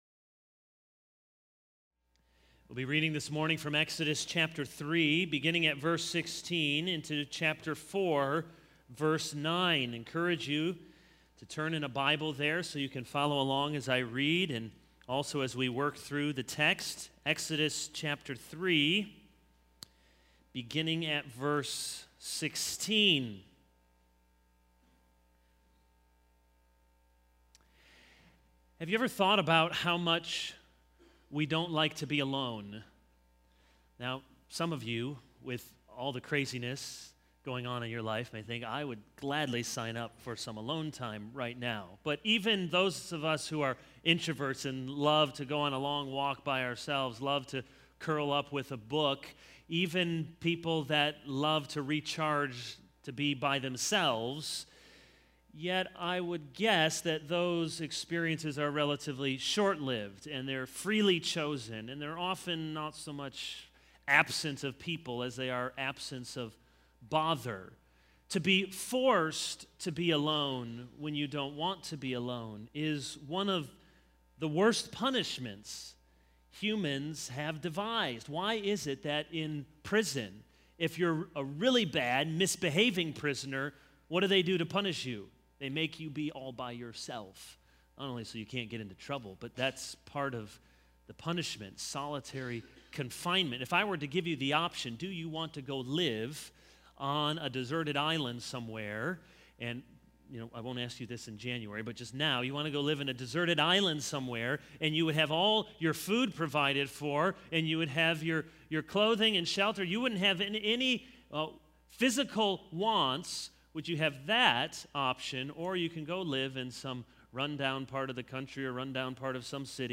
This is a sermon on Exodus 3:16-4:9.